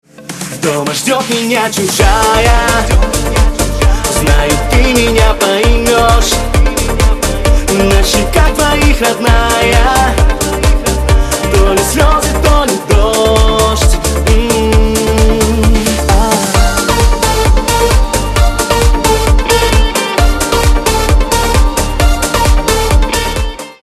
• Качество: 128, Stereo
поп
dance